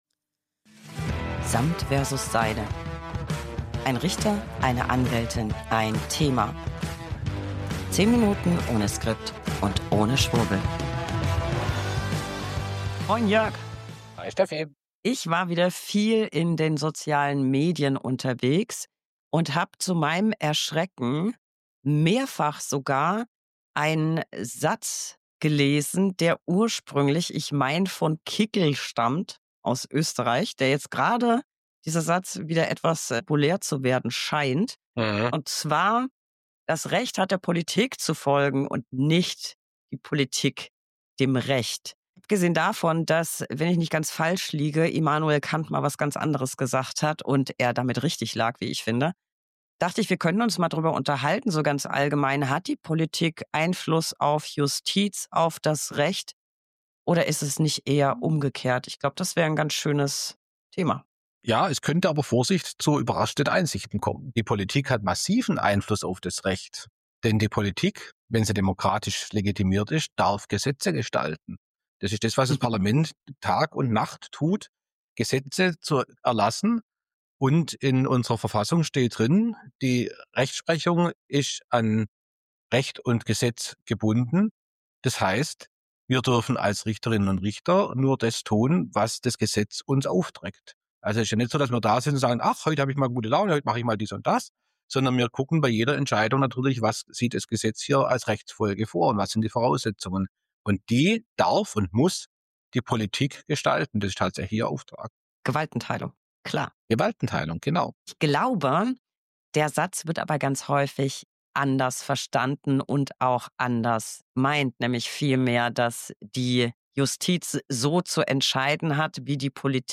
1 Anwältin + 1 Richter + 1 Thema. 10 Minuten ohne Skript und ohne Schwurbel.